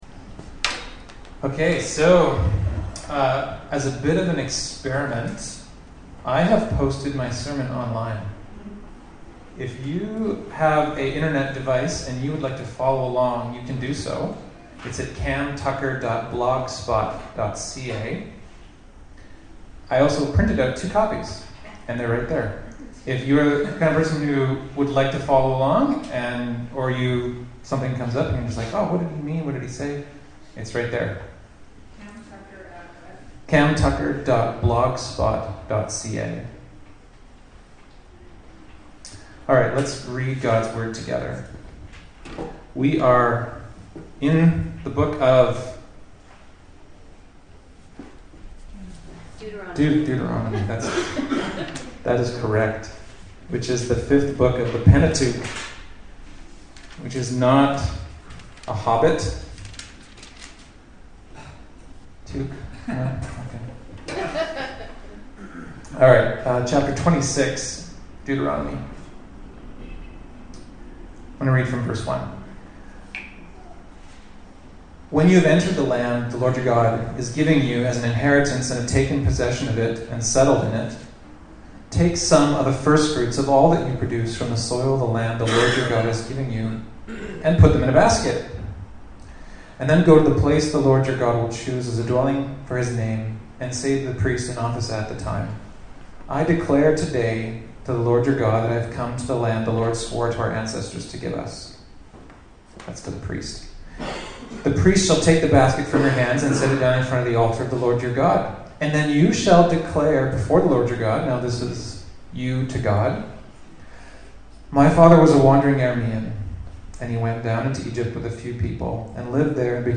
Service Type: Upstairs Gathering